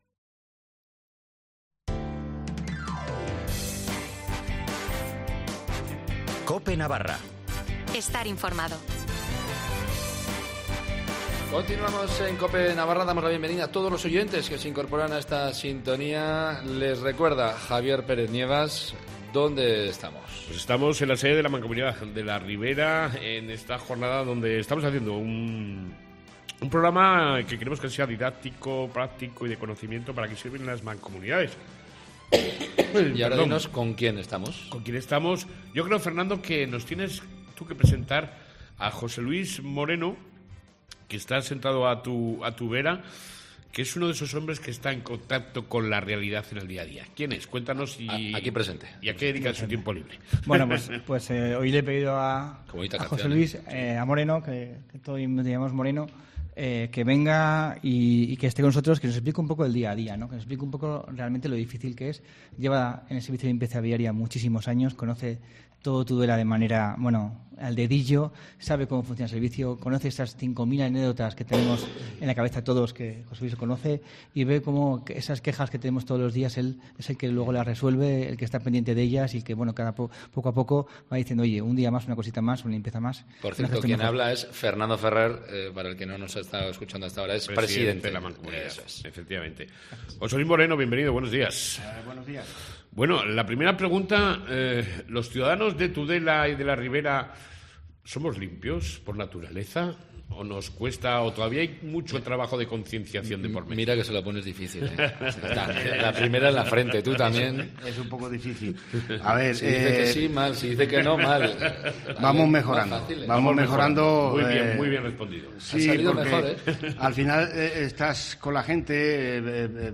Policía Municipal, Policía Foral y Guardia Civil hablan de las actuaciones durante el fin de semana